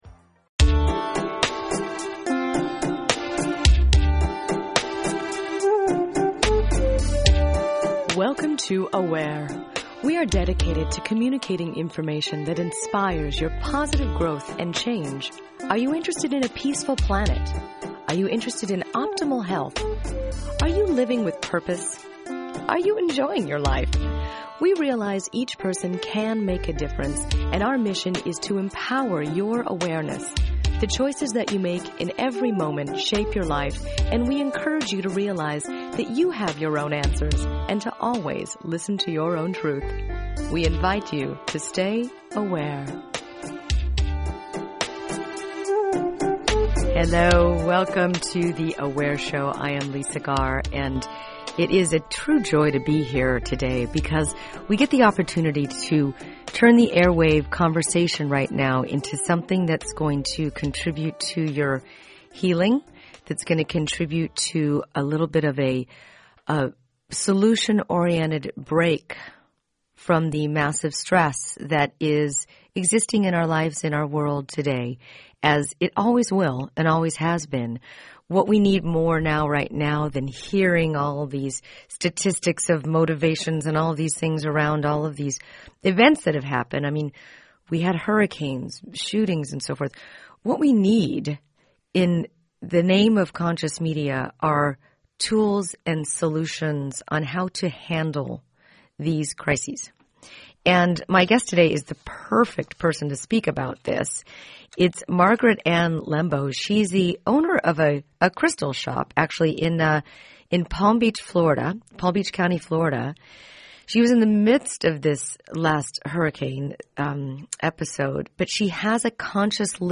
So tune in and learn some new information during this special fund drive show.